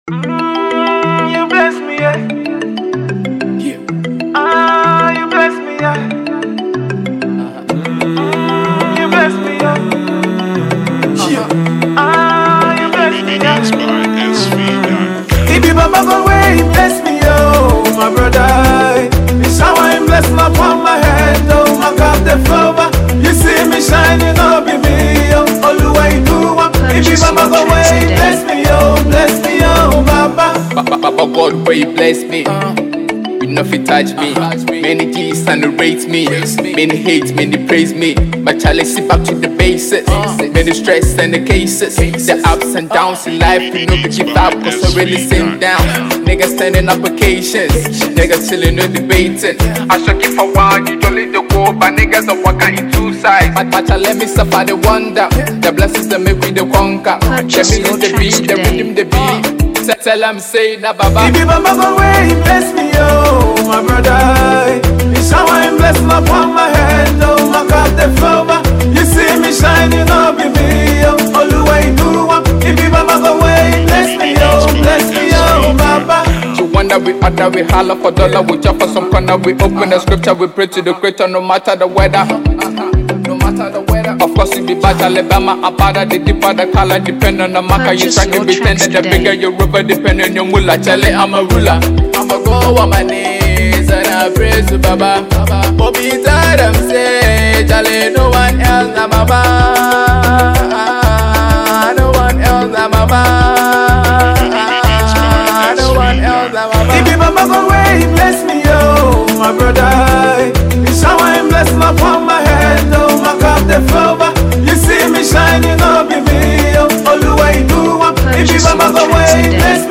Ghana Music
fast rising rappper